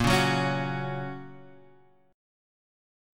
Bbm#5 chord